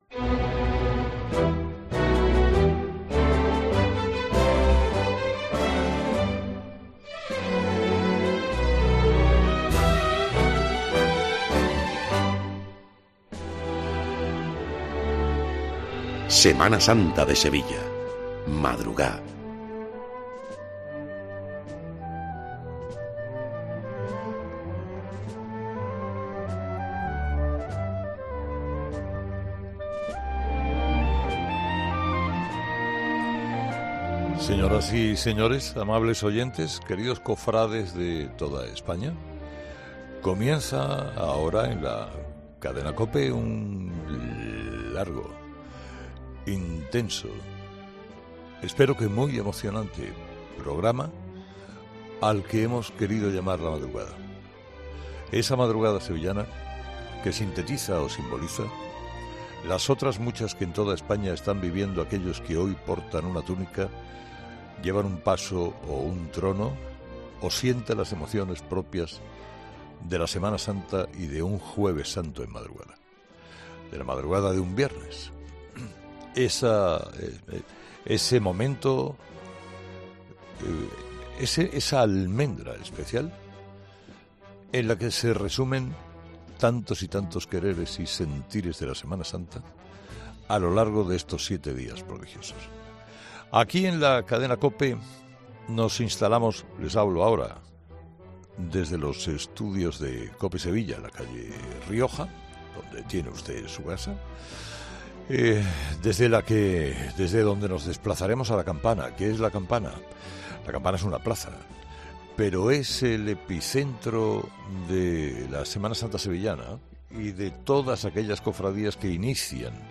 El comunicador ha conectado con las primeras hermandades que procesionarán en la noche más especial de Sevilla